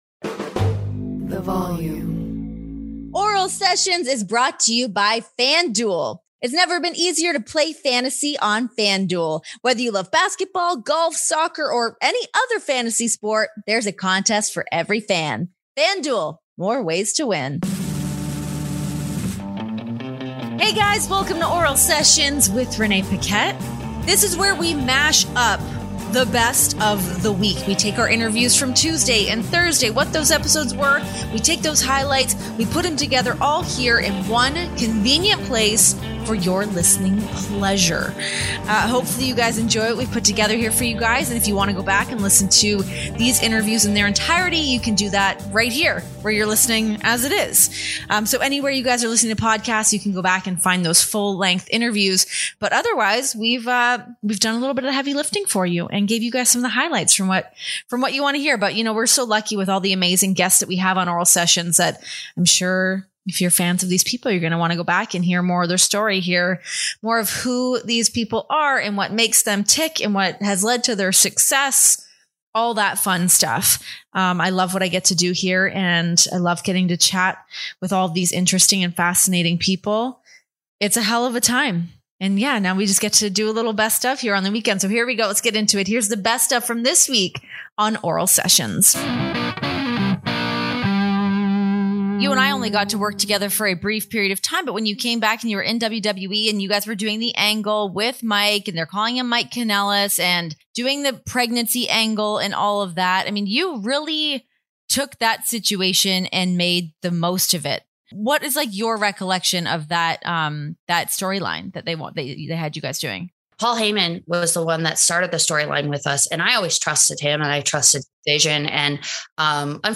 Relive the best of Oral Sessions this week, featuring highlights from our interviews with Maria Kanellis and The Ghost Brothers.